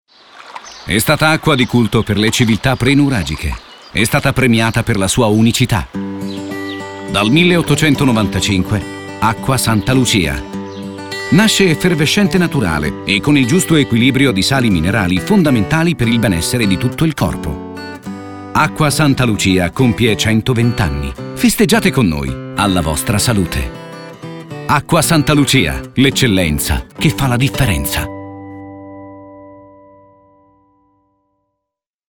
SPOT ISTITUZIONALE